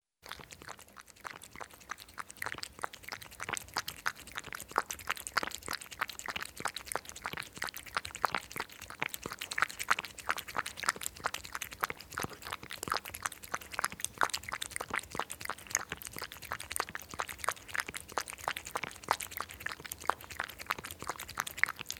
На этой странице собраны натуральные звуки ежей: фырканье, шуршание листьев и другие характерные шумы.
Ёжик ест с хрустом